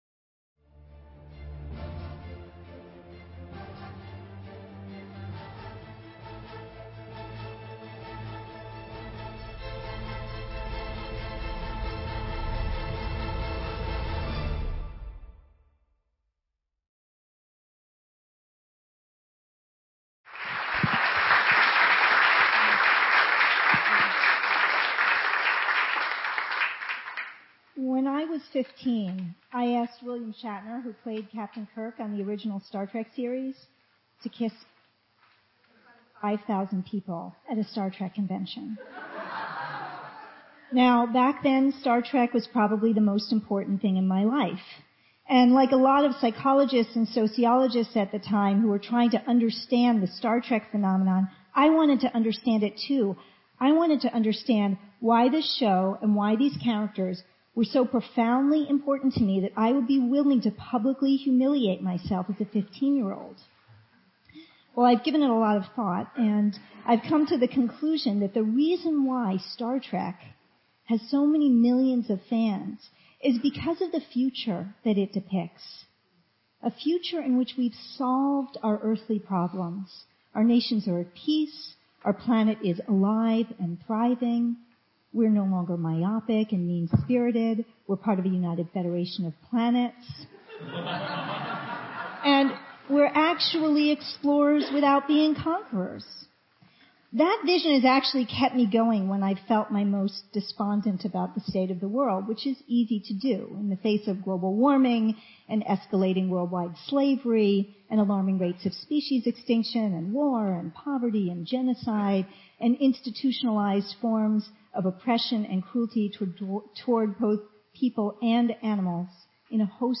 TEDx Talk
TEDxDirigo